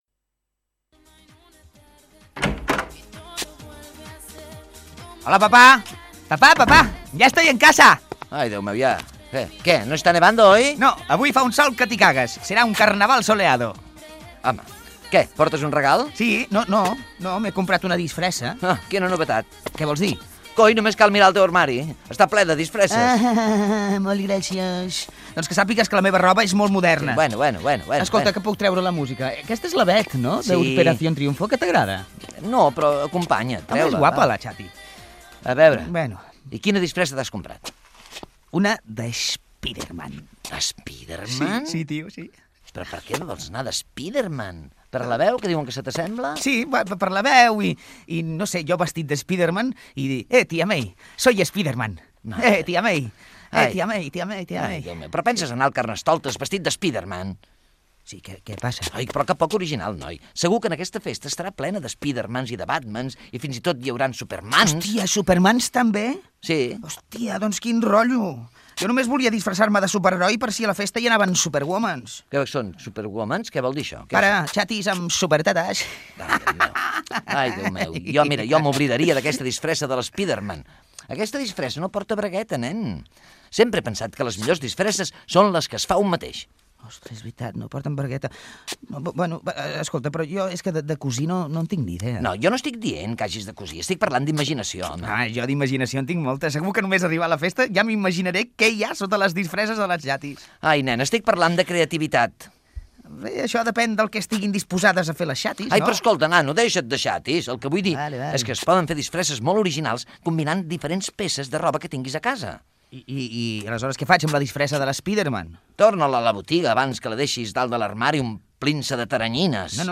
Diàleg entre pare i fill sobre el Carnestoltes